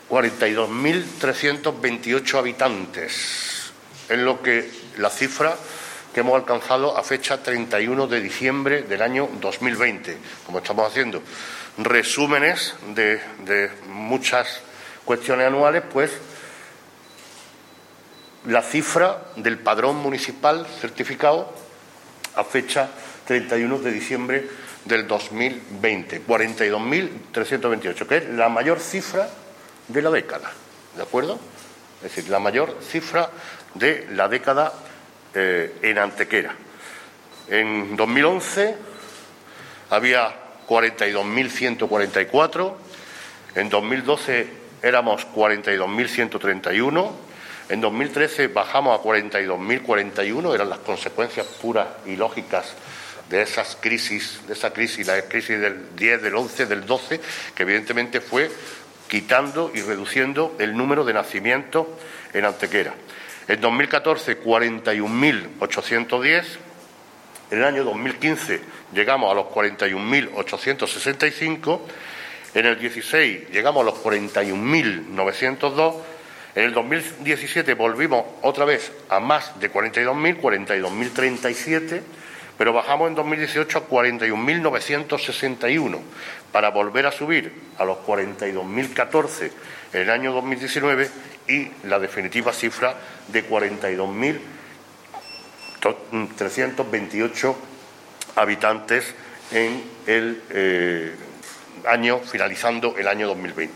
El alcalde de Antequera, Manolo Barón, y el teniente de alcalde delegado de Presidencia y Régimen Interior, Juan Rosas, han informado en rueda de prensa sobre los datos estadísticos del padrón municipal de habitantes de la década que acaba de concluir.
Cortes de voz